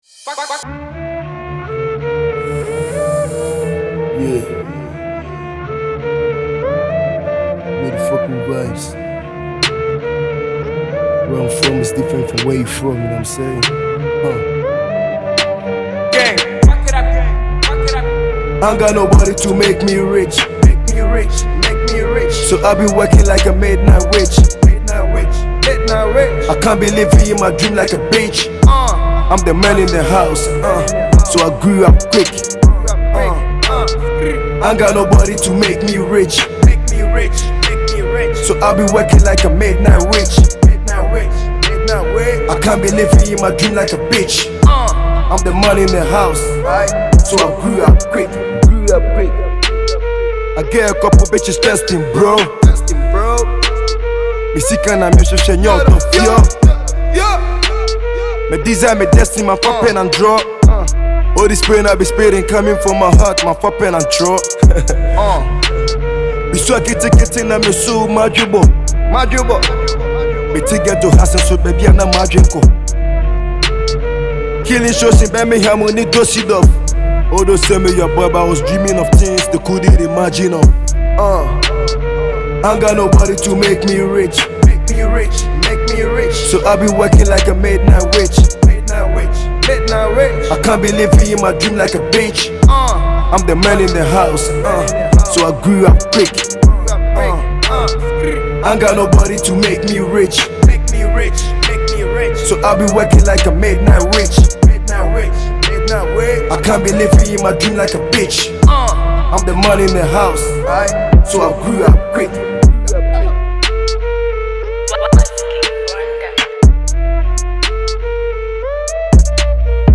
Ghanaian drill sensation
tough Asakaa anthem